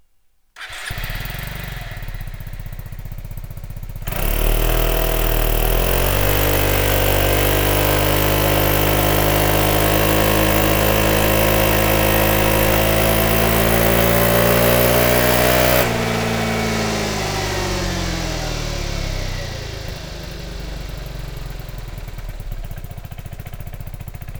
Sound Serienauspuff